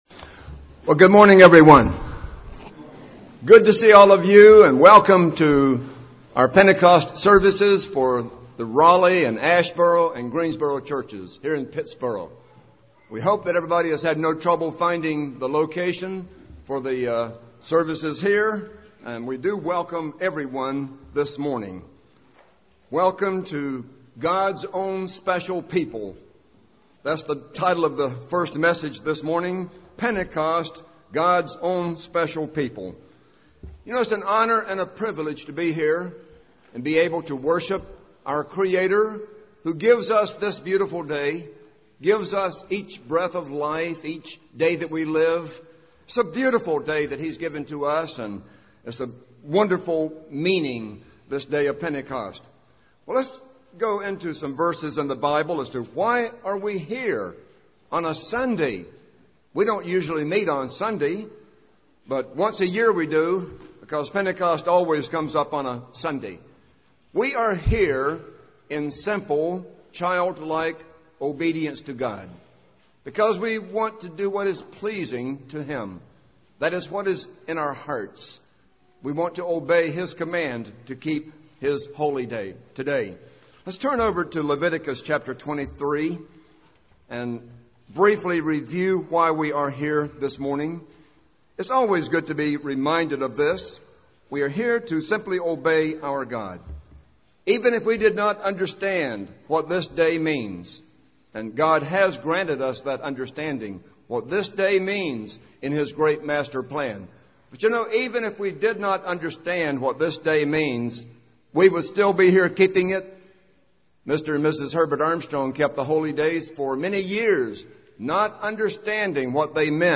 God calls out a people to be His Church UCG Sermon Transcript This transcript was generated by AI and may contain errors.